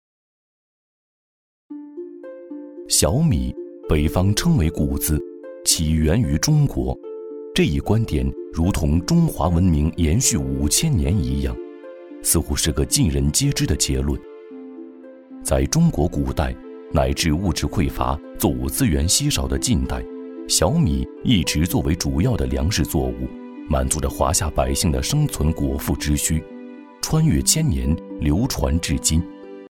配音风格： 轻快 浑厚 科技 欢快